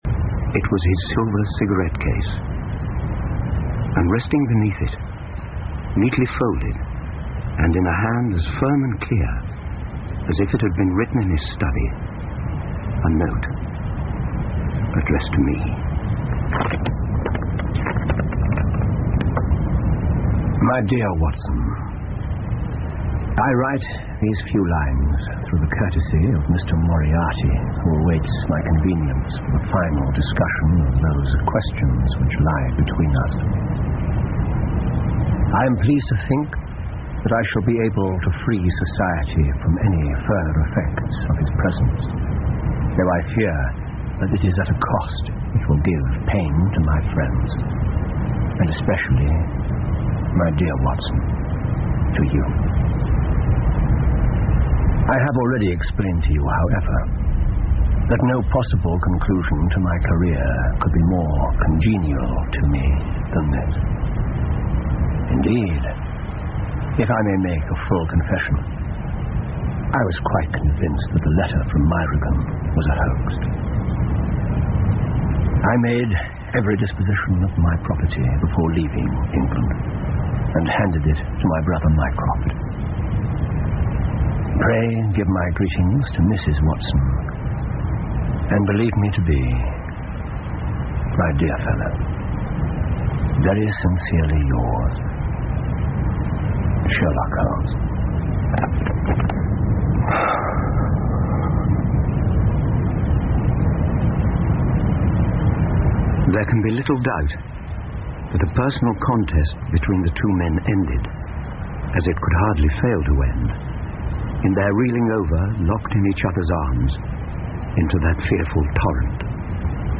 福尔摩斯广播剧 The Final Problem 9 听力文件下载—在线英语听力室